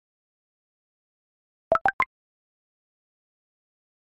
描述：订单提示音
标签： 订单提示音 叮咚 外卖语音播报
声道立体声